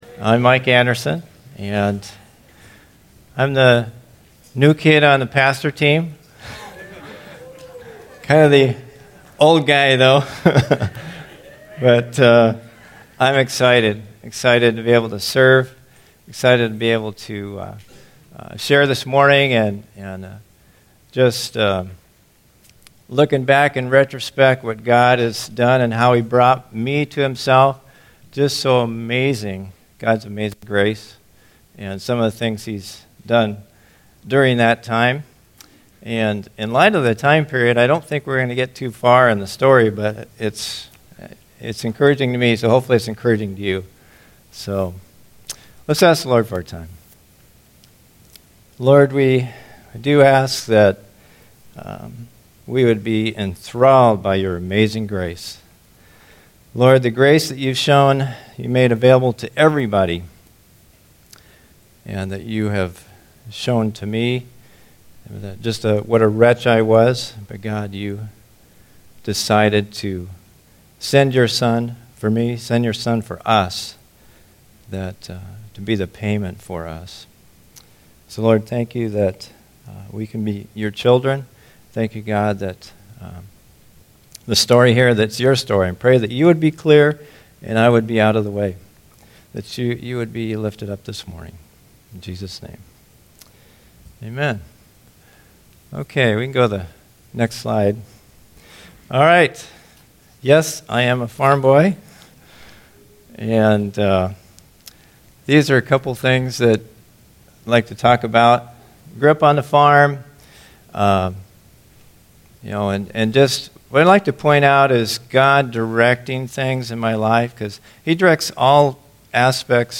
2018 Stay up to date with “ Stonebrook Church Sermons Podcast ”